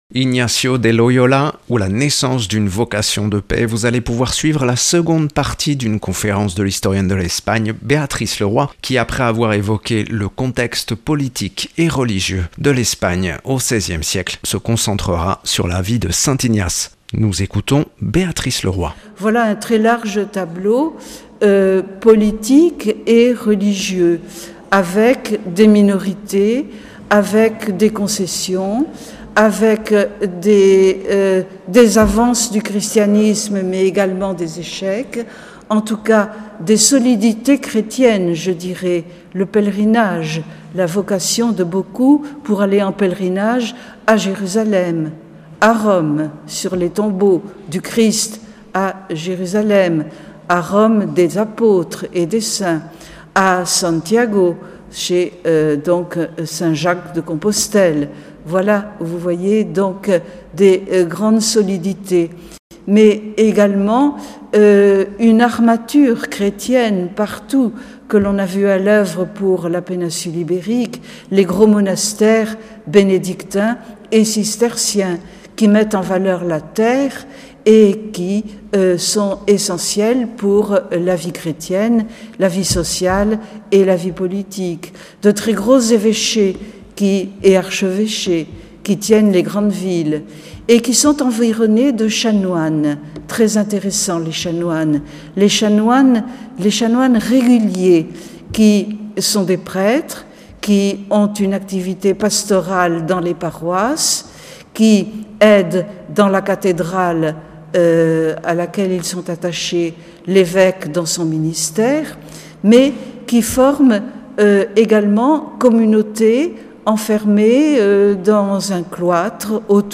(Enregistré le 30/11/2022 à la cathédrale de Bayonne.)